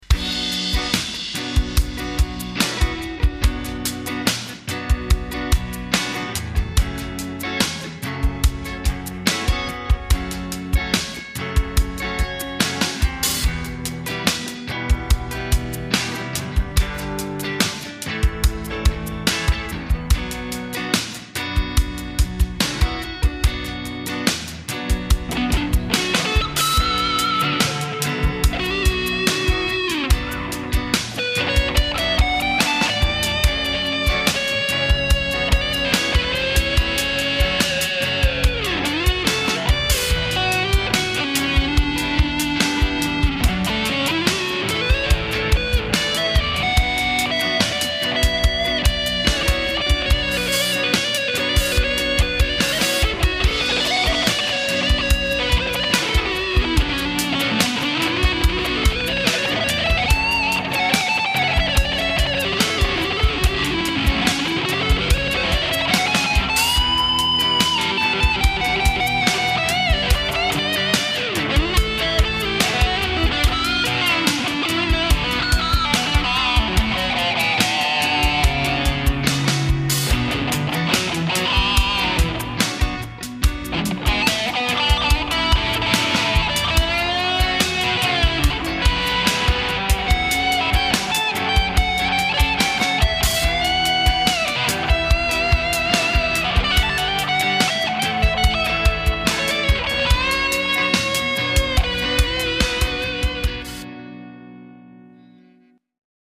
guitar hero - melancolie - rock - guitare electrique - live